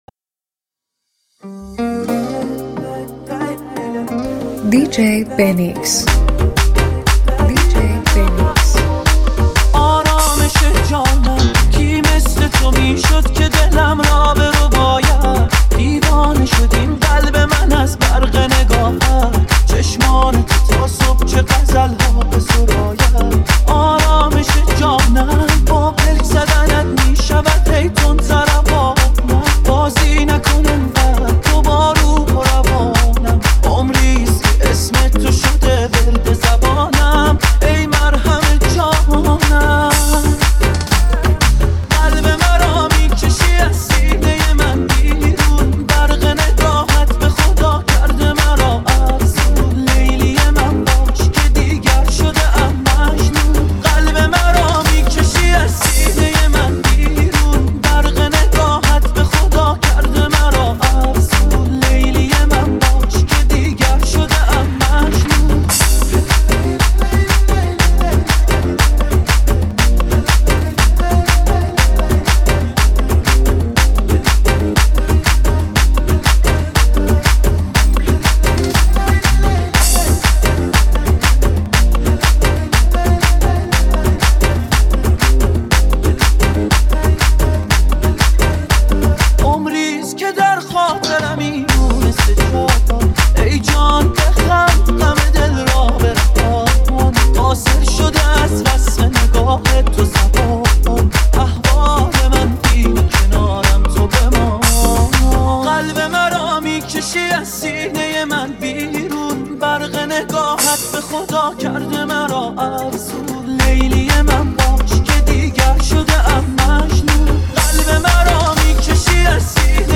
یه ریمیکس شاد و پرهیجان که محاله نلرزونتت!